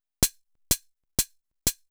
HIHAT003_DISCO_125_X_SC3.wav